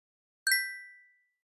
Уведомления, оповещения, сообщения гугл почты Gmail в mp3
7. Gmail notification sweet